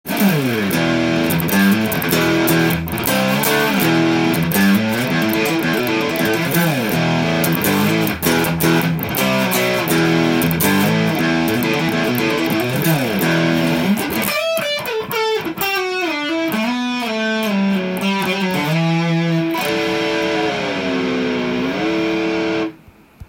歪ませるとメサブギーで弾いているような
目の細かい音になります。
まさにヘビメタまたはロックの最適なギターと言えます。